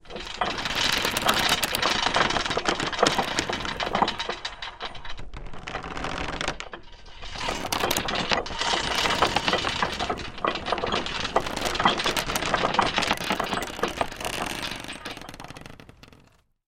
Звук натяжения механизма катапульты перед выстрелом